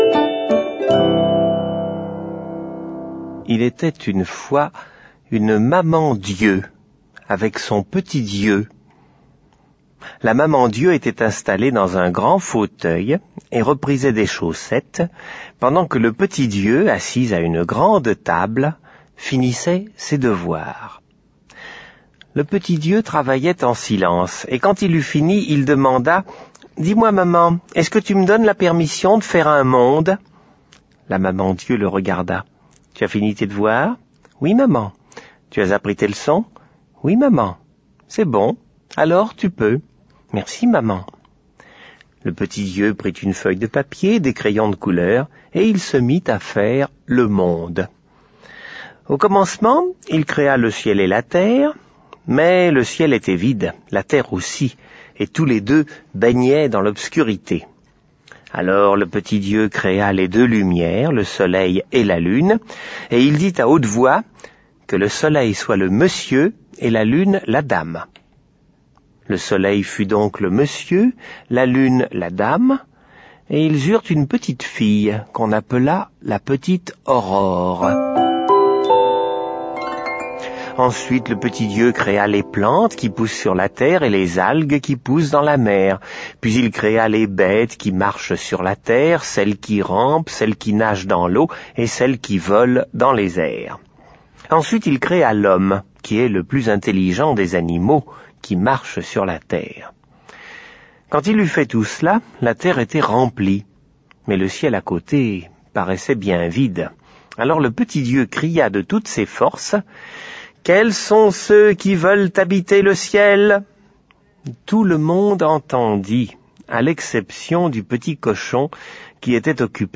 Genre : Livre Audio.